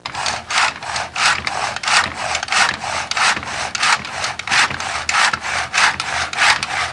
Hand Saw Sound Effect
Download a high-quality hand saw sound effect.
hand-saw-1.mp3